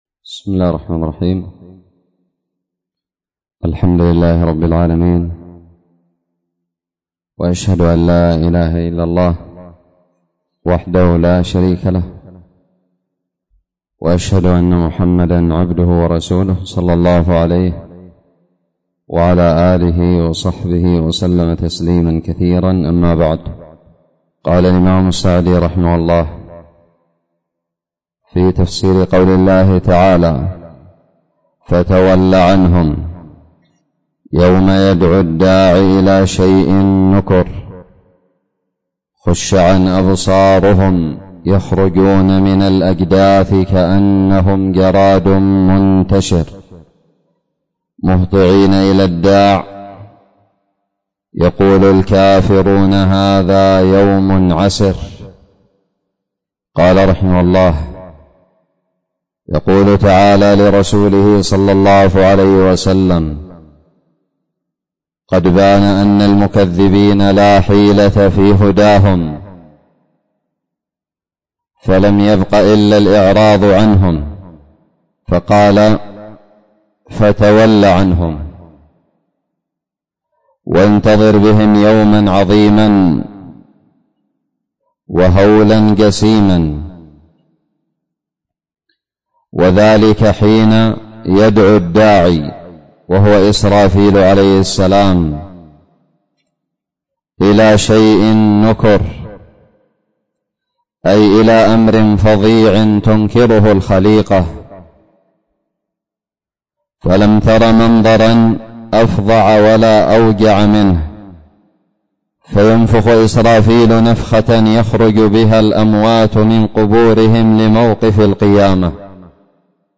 الدرس الثاني من تفسير سورة القمر
ألقيت بدار الحديث السلفية للعلوم الشرعية بالضالع